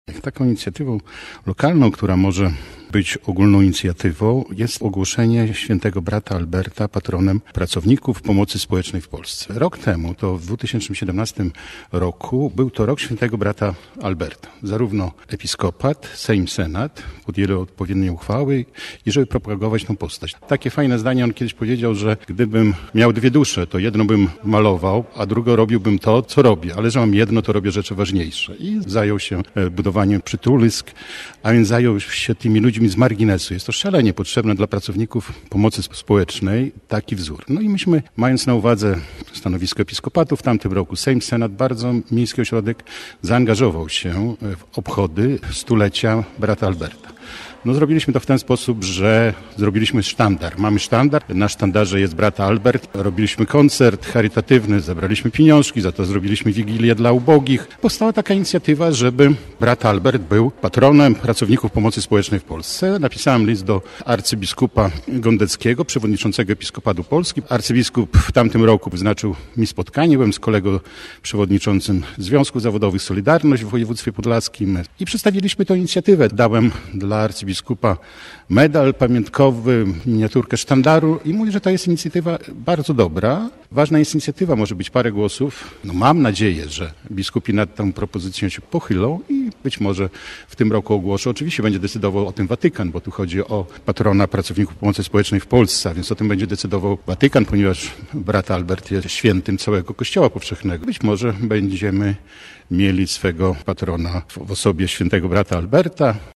XVIII Ogólnopolska Konferencja Pracowników Socjalnych